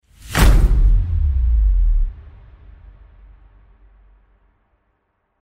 Звуки Impact
Слушайте онлайн и скачивайте бесплатно качественные ударные эффекты, которые идеально подойдут для монтажа видео, создания игр, рекламных роликов и постов в соцсетях.